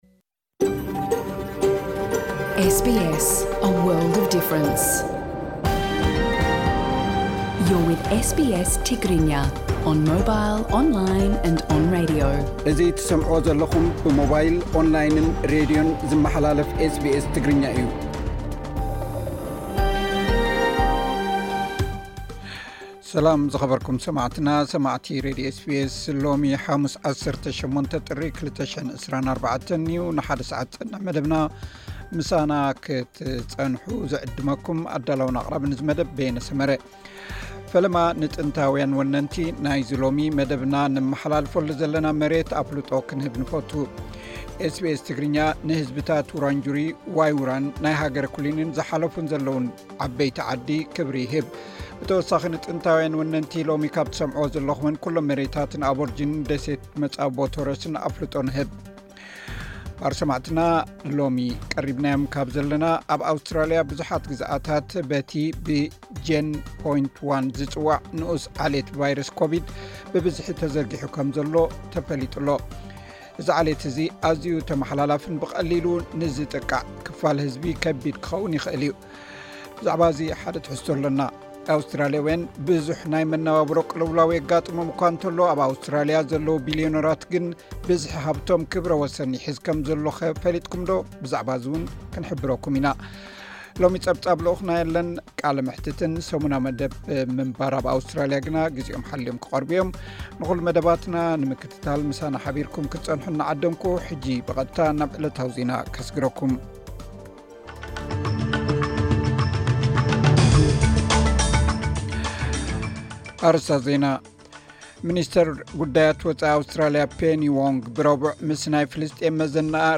ሎሚ ጸብጻብ ል ኡኽና የለን፡ ቃለ መሕትትን ሰሙናዊ ምንባር ኣብ ኣውስትራሊያ ግና ግዜኦም ሓልዮም ዝቐርቡ መደባትና እዮም።